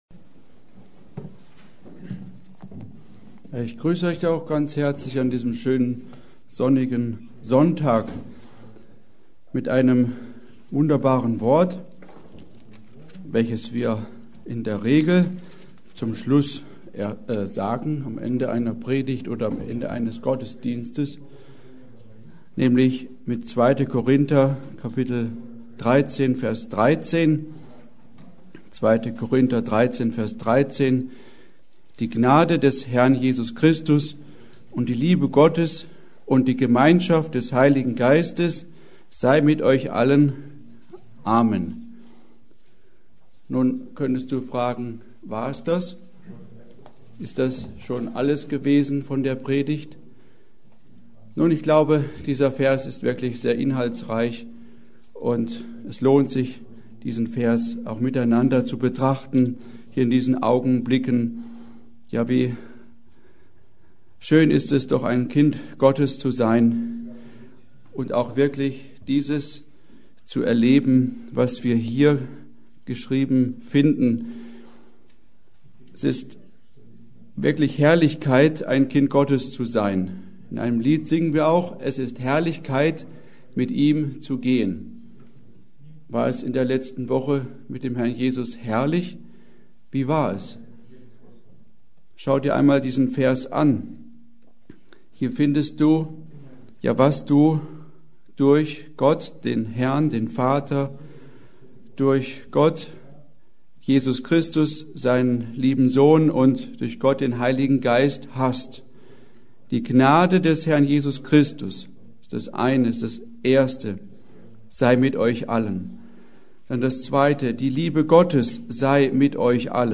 Predigt: Die Gnade unseres Herrn Jesus Christus